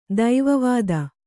♪ daiva vāda